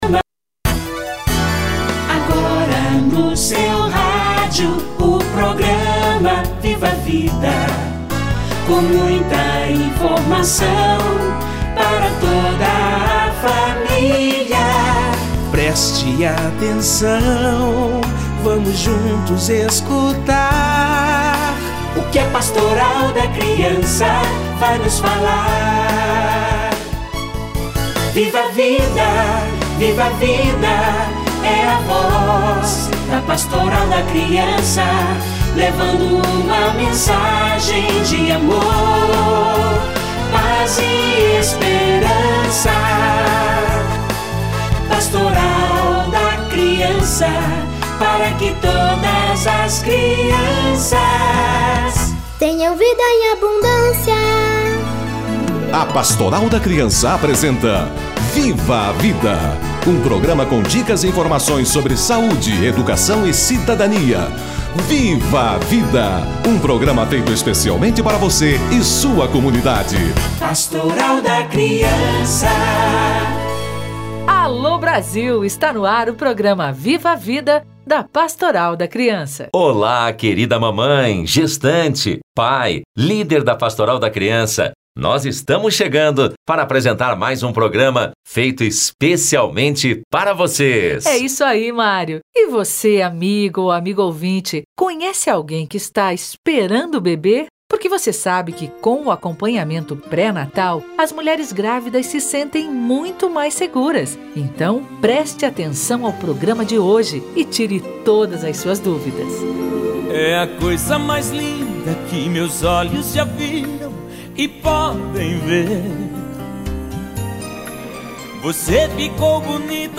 Acompanhamento na gestação - Entrevista